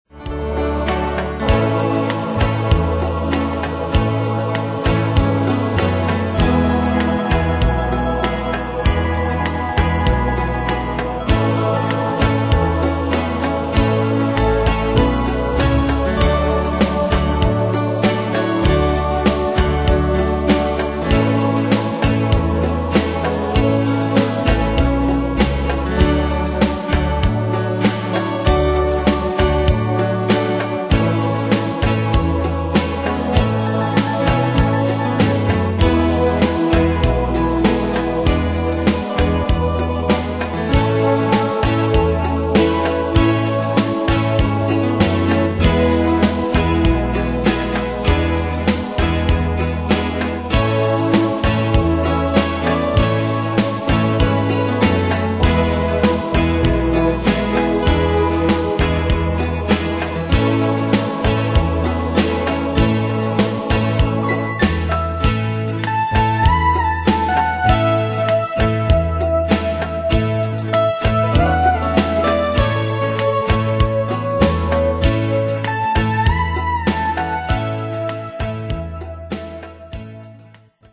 Style: Country Année/Year: 1993 Tempo: 98 Durée/Time: 4.38
Danse/Dance: Rhumba Cat Id.
Pro Backing Tracks